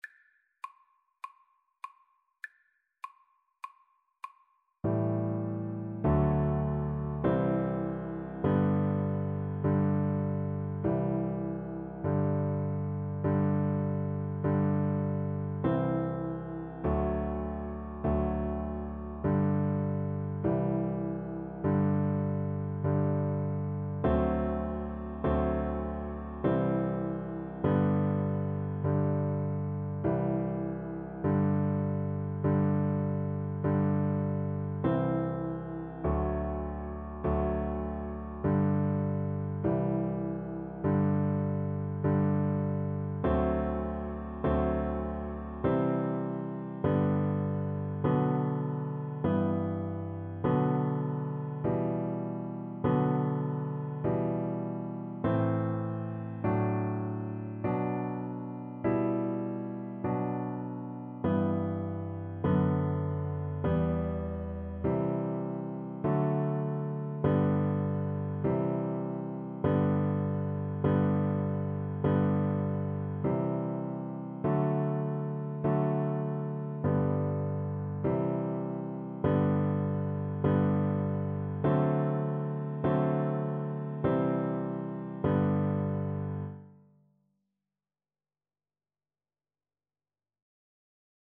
Free Sheet music for Piano Four Hands (Piano Duet)
G major (Sounding Pitch) (View more G major Music for Piano Duet )
4/4 (View more 4/4 Music)